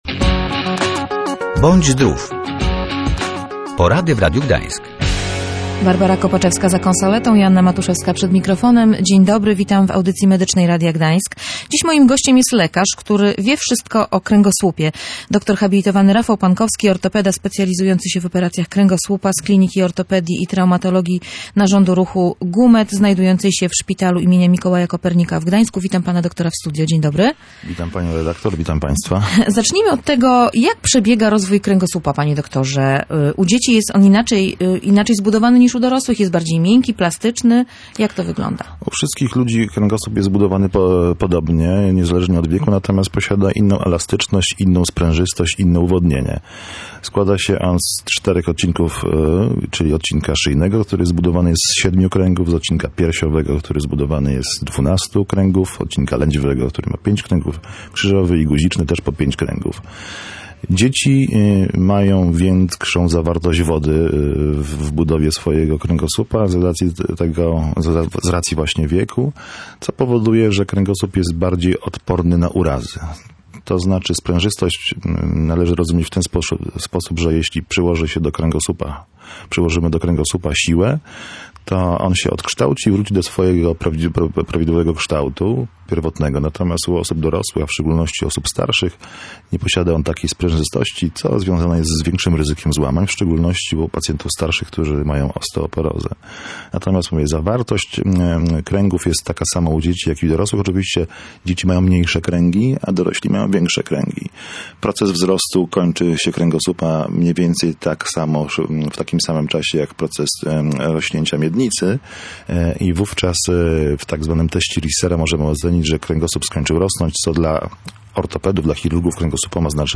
Można mieć pęknięty, albo złamany kręgosłup i w ogóle o tym nie wiedzieć - mówił w Radiu Gdańsk ortopeda, dr